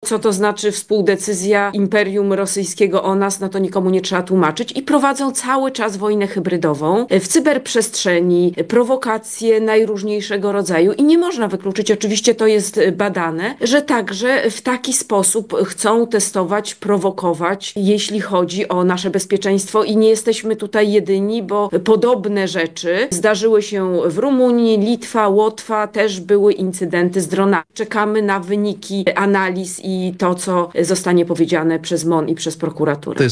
Rosja zupełnie jawnie pokazuje swoje agresywne zamiary wobec innych państw, w tym Polski – komentuje incydent z Osin minister Funduszy i Polityki Regionalnej Katarzyna Pełczyńska Nałęcz. Była ambasador Polski w Federacji Rosyjskiej w porannej rozmowie Radia Lublin stwierdziła, że rosyjski reżim pod wodzą Władimira Putina chciałby decydować o bezpieczeństwie Polski: